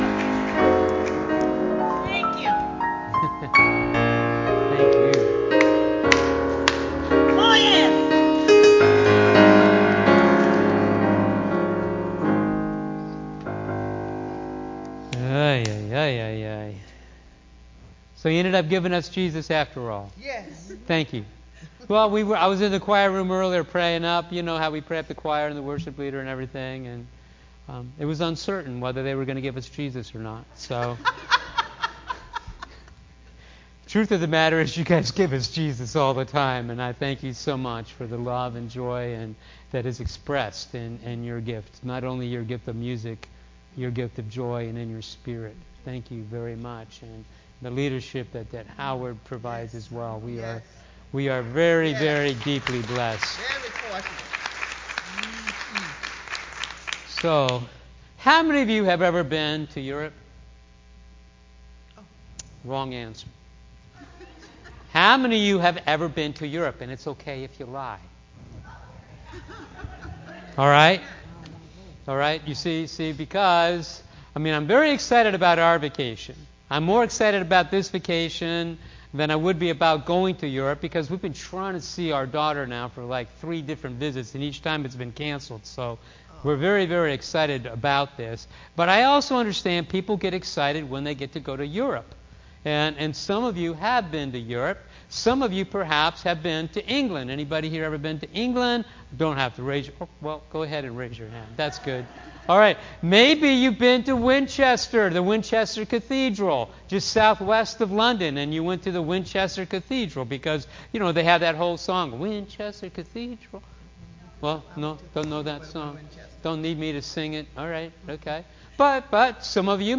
Click here to watch this week's full Sunday Service